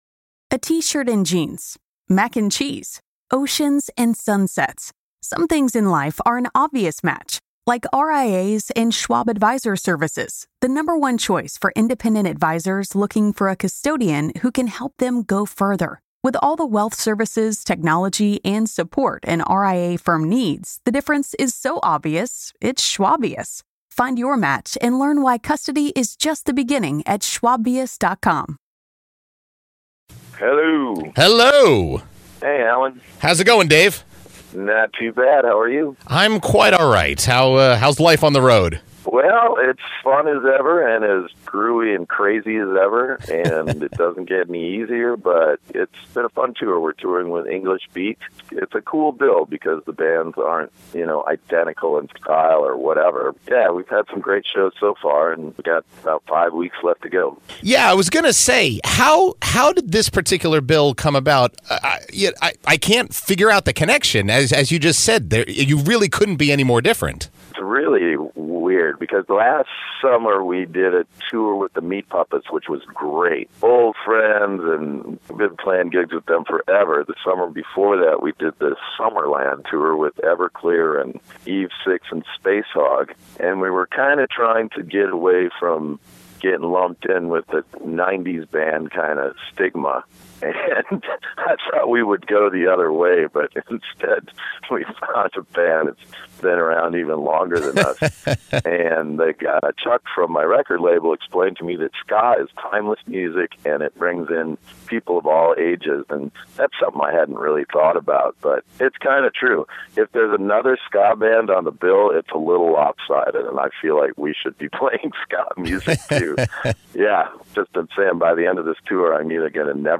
interview with Soul Asylum's Dave Pirner